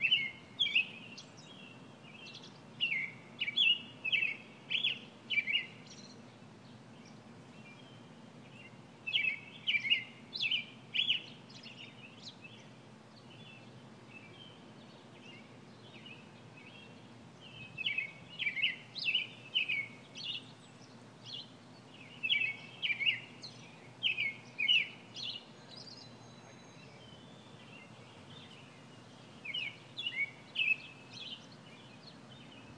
Robin song